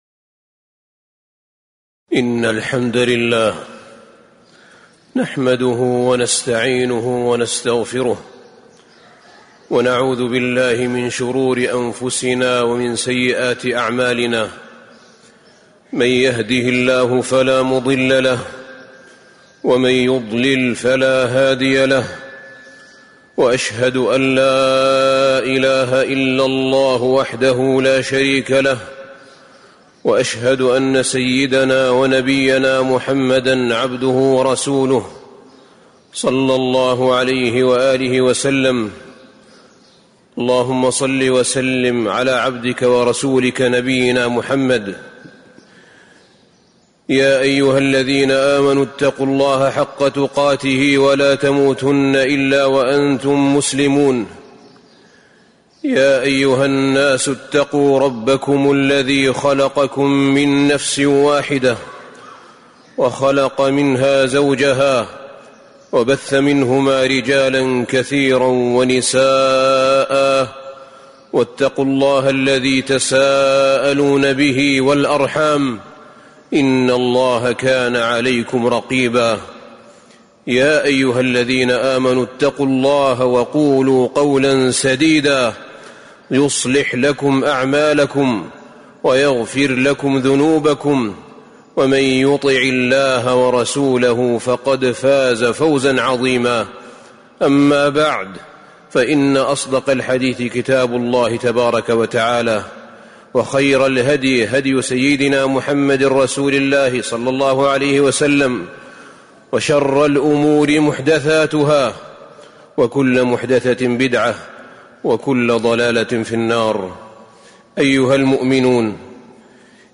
تاريخ النشر ١٩ رجب ١٤٤٤ هـ المكان: المسجد النبوي الشيخ: فضيلة الشيخ أحمد بن طالب بن حميد فضيلة الشيخ أحمد بن طالب بن حميد من فضائل التوبة والاستغفار